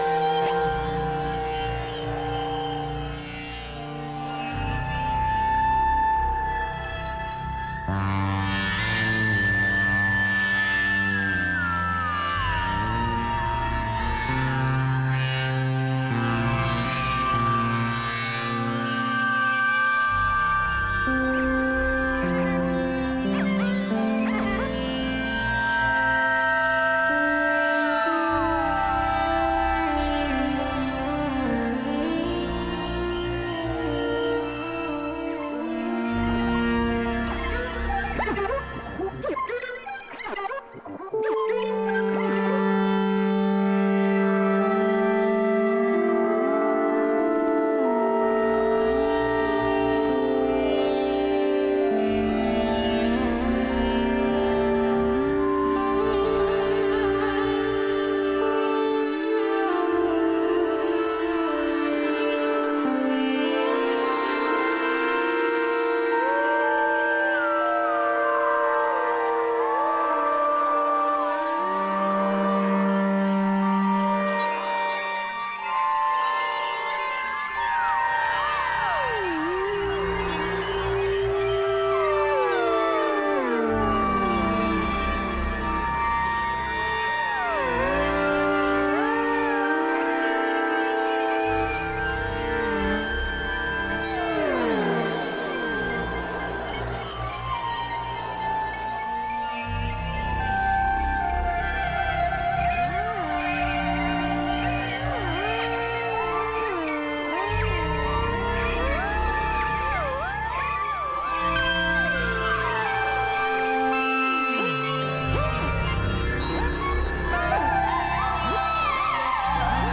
a glitchy, cut-up take on U.S house
a beat-less, late night trip